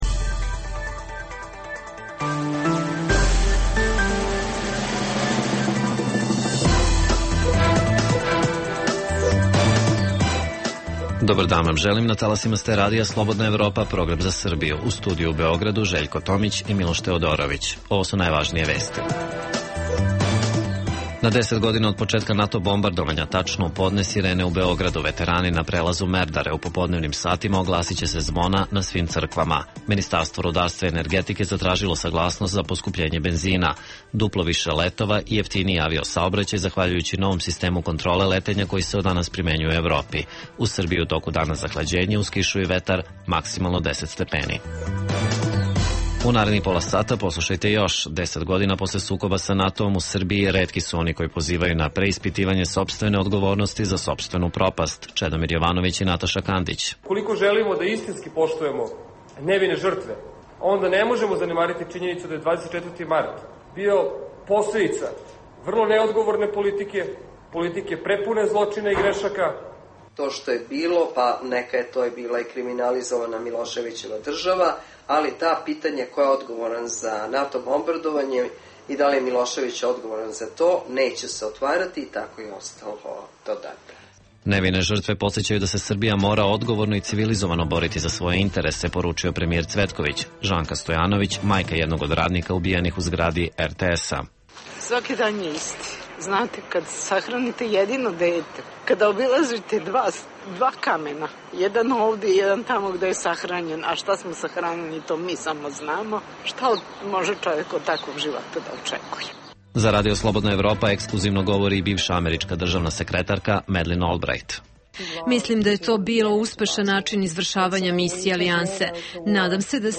Povodom godišnjice od početka NATO bombardovanja Srbije govore: premijer Mirko Cvetković, vicepremijer Ivica Dačić, ministar odbrane Dragan Šutanovac, Kragujevčani i Beograđani.